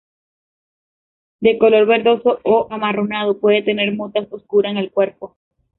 ver‧do‧so
/beɾˈdoso/